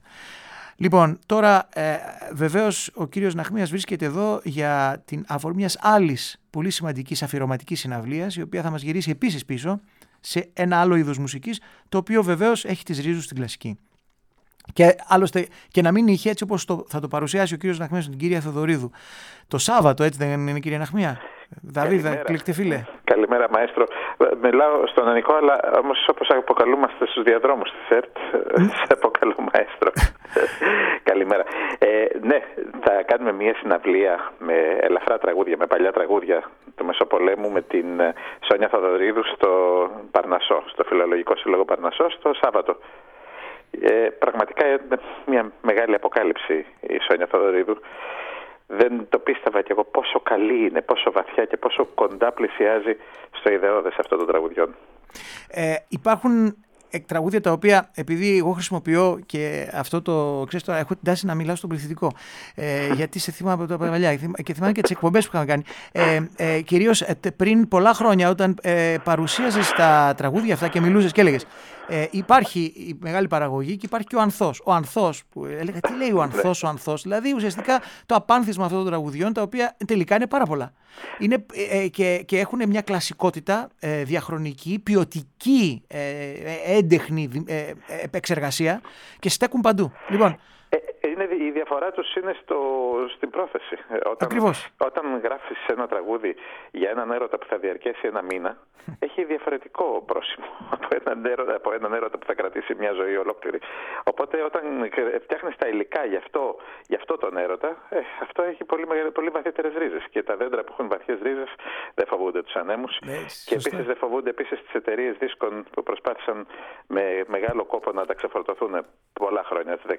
Καλεσμένος στην εκπομπή